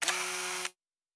pgs/Assets/Audio/Sci-Fi Sounds/Mechanical/Servo Small 6_2.wav at master
Servo Small 6_2.wav